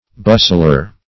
bustler - definition of bustler - synonyms, pronunciation, spelling from Free Dictionary
Bustler \Bus"tler\ (b[u^]s"sl[~e]r)